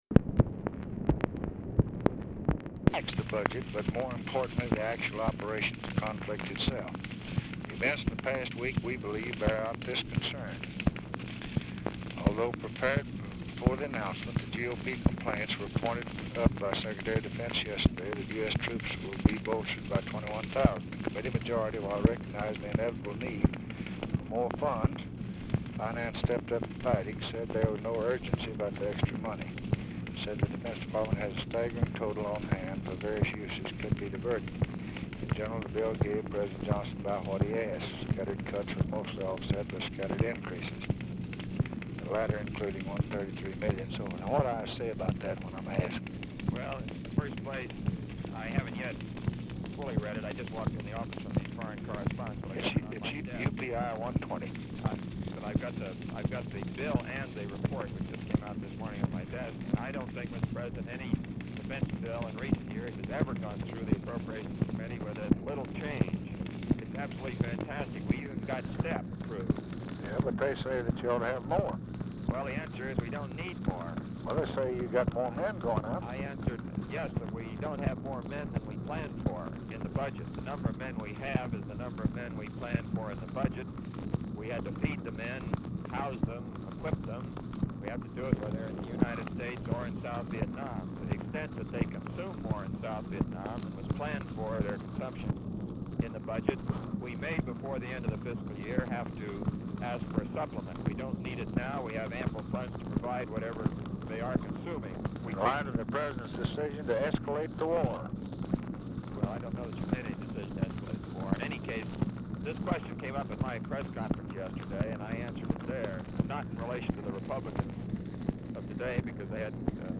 RECORDING STARTS AFTER CONVERSATION HAS BEGUN; POOR SOUND QUALITY; TICKER TAPE AUDIBLE IN BACKGROUND
Format Dictation belt
Specific Item Type Telephone conversation